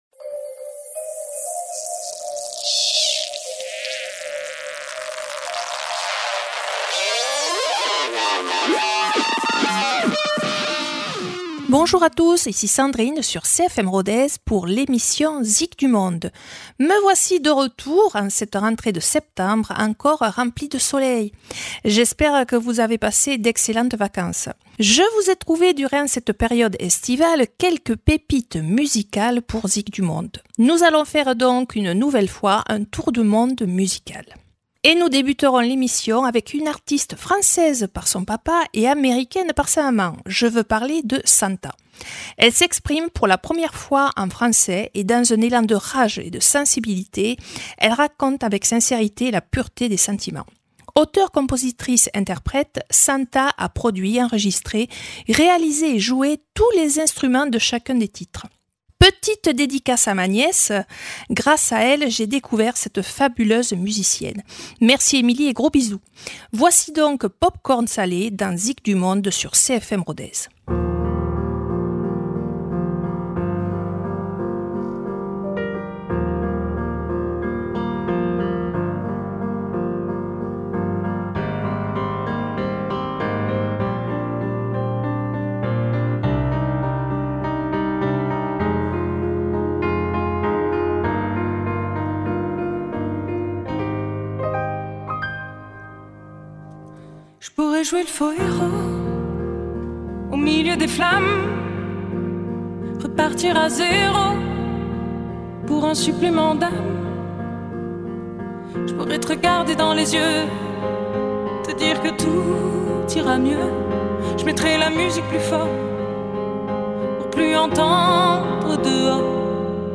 la rentrée sera sympathique et musicale avec un tour du monde aux jolies notes encore estivales.